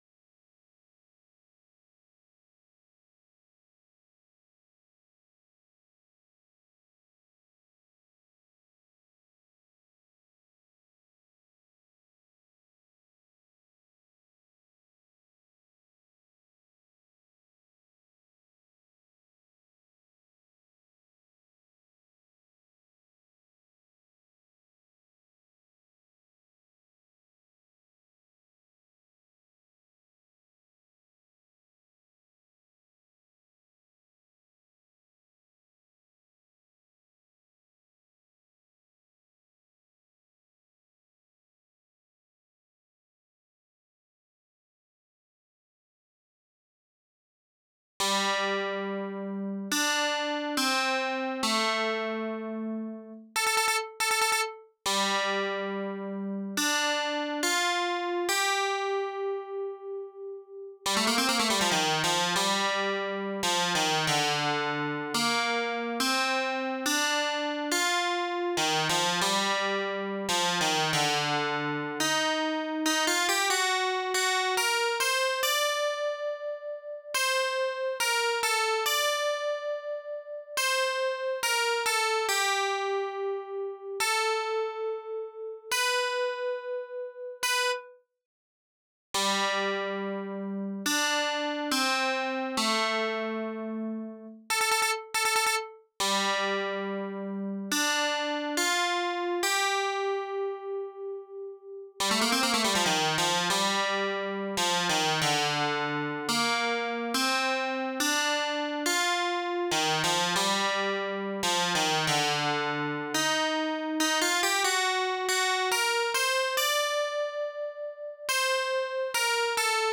Ys Guitarsynth 170bpm
Ys-guitarsynth-170bpm.wav